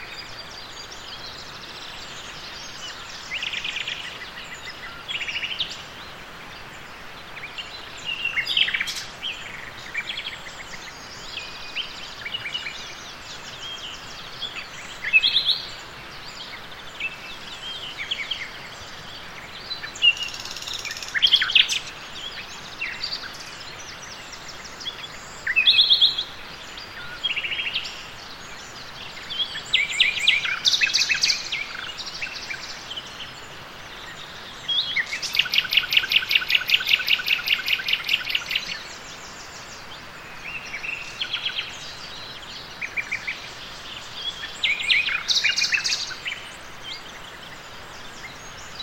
nightingales_singing_rAa.wav